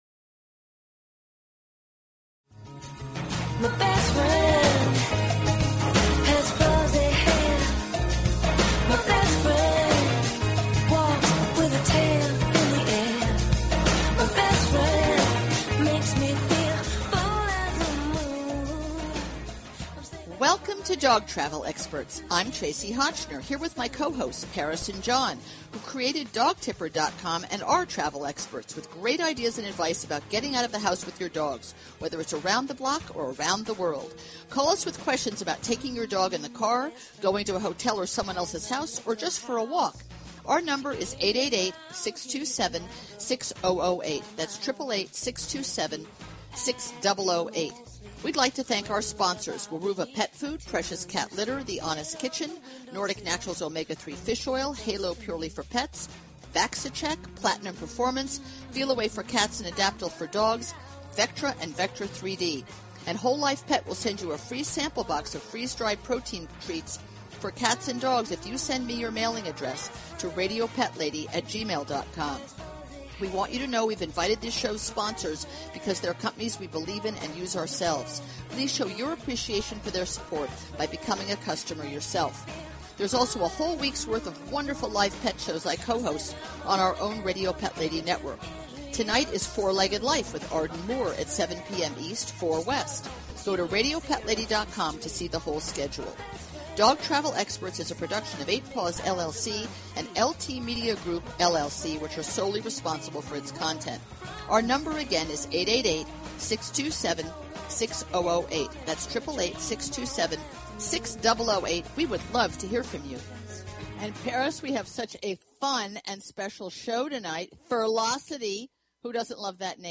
Talk Show Episode, Audio Podcast, Dog_Travel_Experts and Courtesy of BBS Radio on , show guests , about , categorized as